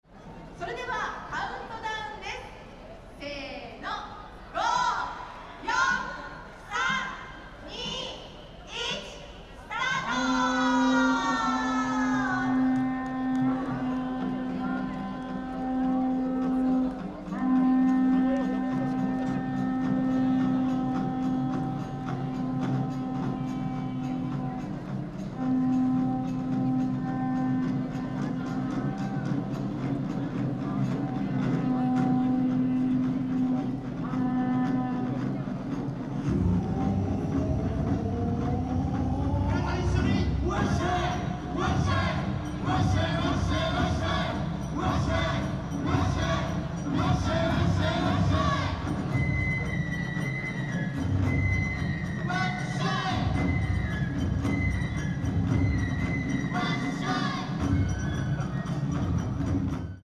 In front of Machi-naka Square
On a so hot day, Waraji (Japanese Sandal) festival was held on the street in front of Machi-Naka square.